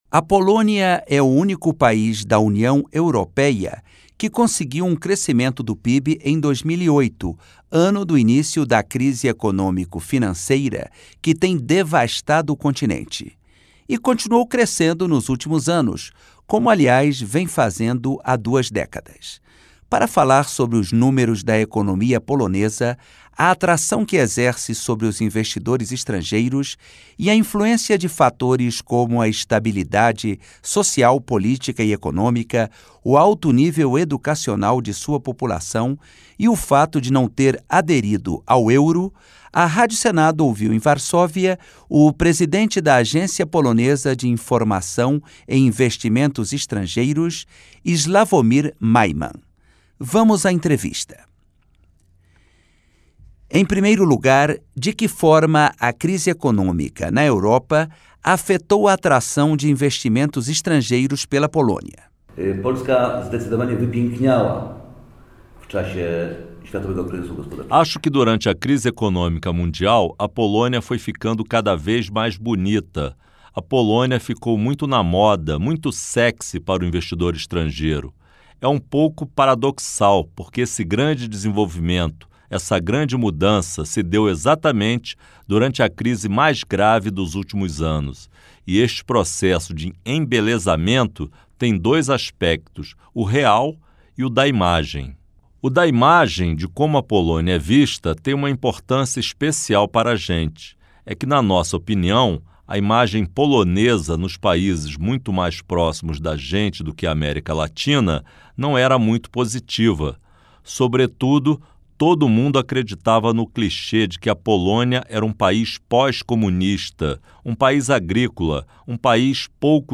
Entevista